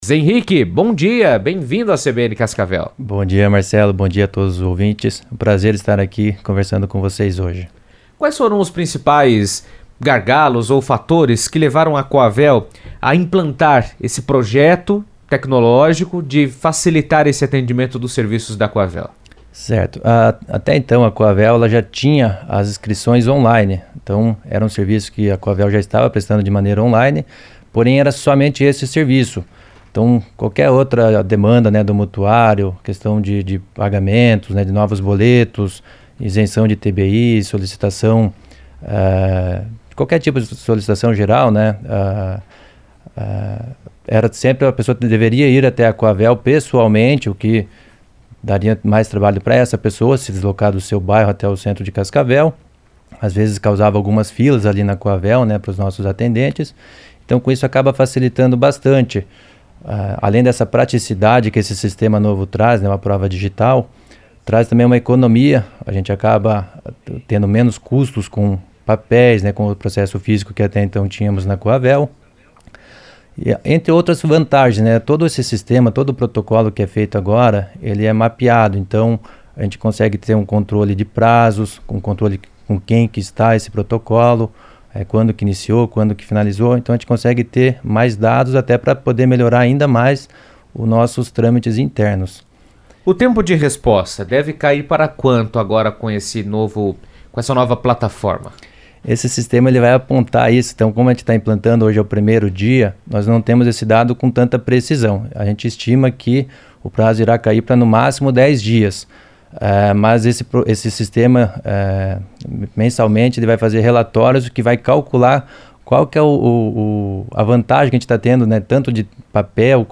Com o objetivo de tornar o atendimento à comunidade mais ágil e eficiente, a Companhia de Habitação de Cascavel apresentou, nesta quinta-feira (21), as mudanças que marcam a nova forma de solicitar, tramitar e acompanhar os pedidos junto à Cohavel. A partir de agora, todos os processos, internos e externos, passam a ser realizados de maneira totalmente digital, eliminando etapas manuais e deslocamentos desnecessários. Henrique Milani, presidente da Cohavel, esteve na CBN para detalhar as novidades.